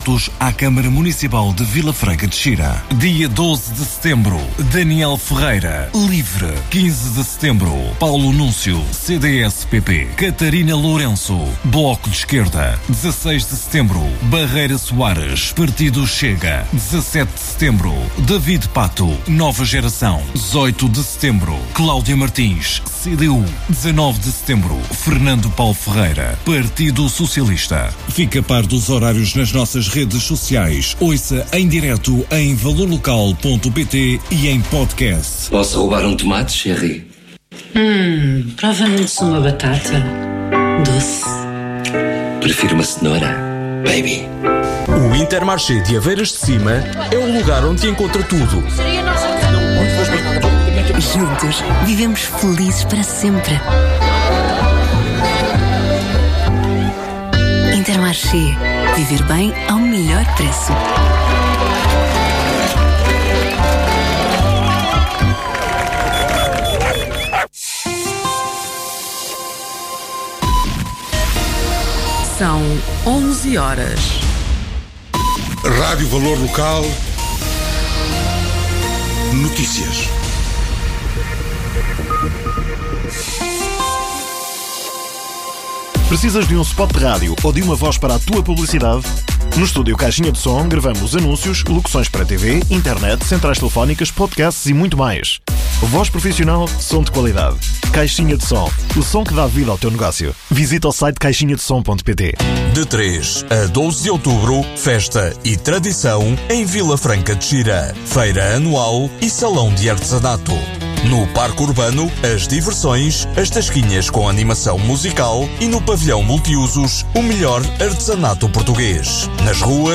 Em entrevista à Rádio Valor Local, Paulo Núncio, candidato do CDS-PP à autarquia e atual deputado na Assembleia da República, desvaloriza o facto de nestas eleições o partido ir a votos fora da coligação com o PSD, até porque segundo o mesmo não lhe interessa integrar uma candidatura onde está a Iniciativa Liberal. Entre as suas medidas está diminuir os impostos no IRC com a possibilidade de as família conseguirem um benefício de 300 euros em cada ano. O candidato promete ainda eliminar a derrama, imposto sobre as empresas.